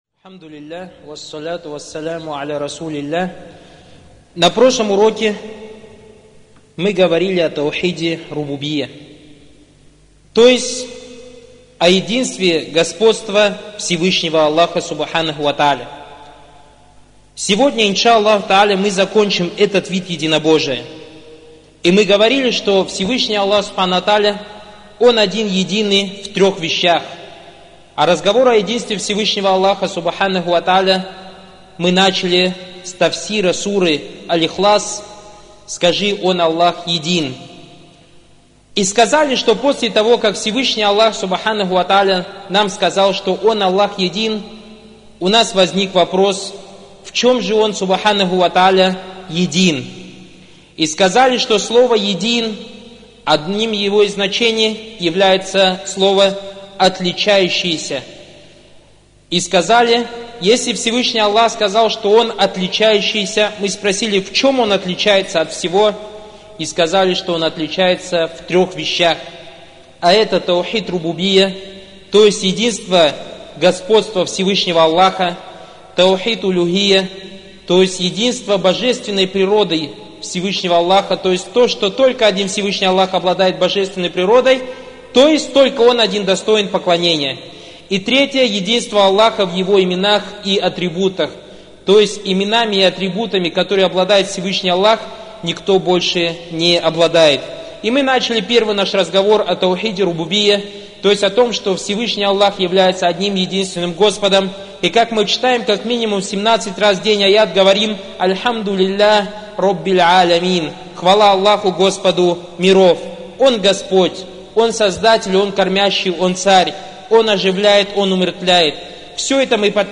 Материал: Лекции